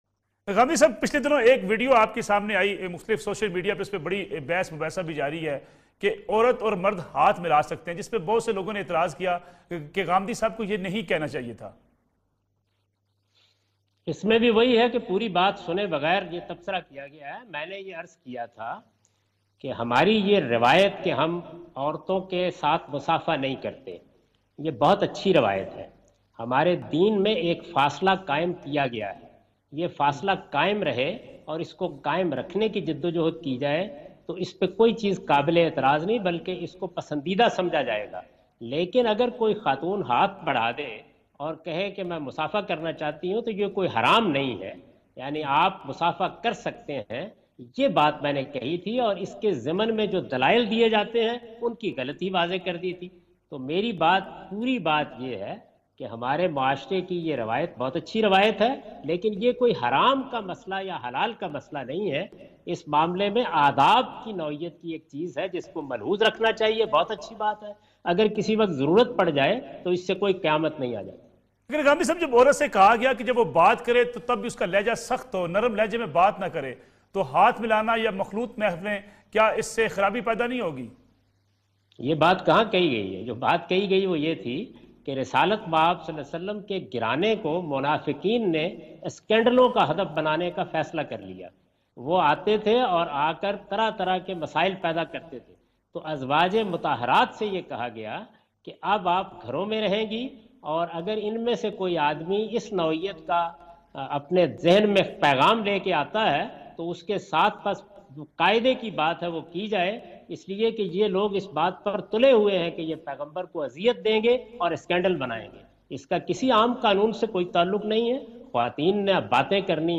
Category: TV Programs / Neo News /
In this program Javed Ahmad Ghamidi answer the question about "Man and Women Handshake" on Neo News.